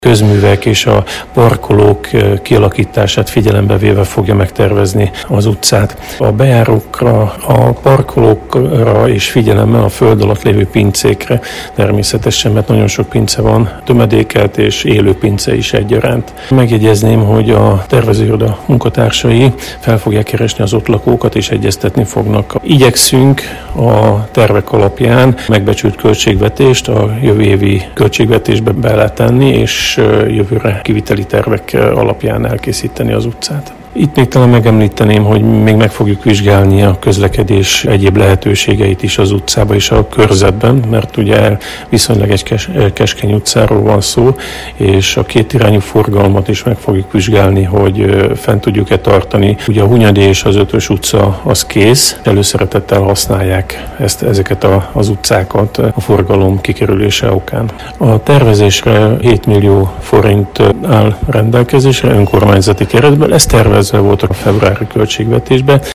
Kedden délelőtt sajtótájékoztatót tartott Horváth Zoltán önkormányzati képviselő a Lánc utca út- és közmű felújítása kapcsán. A 3.számú választókerület képviselője, közbiztonságért felelős tanácsnok rádiónknak elmondta, a tervezővel megkötöttek a szerződést, két hónap alatt készítik el a terveket, melyekhez a föld feletti és a föld alatti lehetőségeket is feltárják.